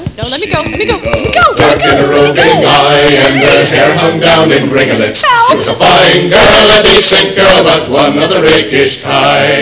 vocals, violin
vocals, bodhran
-- vocals, guitar, pennywhistle
vocals, octave mandolin, hammered dulcimer